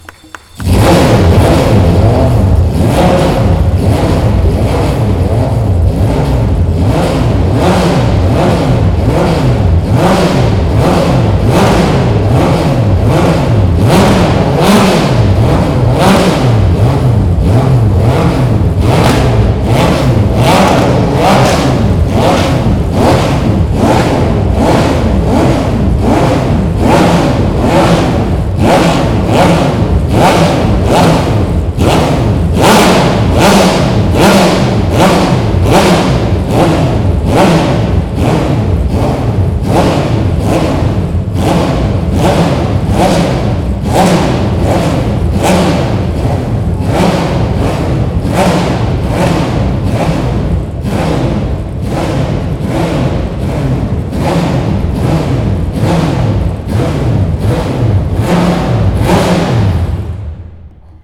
Motorsounds und Tonaufnahmen zu Porsche Fahrzeugen (zufällige Auswahl)
Porsche 911 GT3 RSR (2004) - Porsche Soundnacht 2018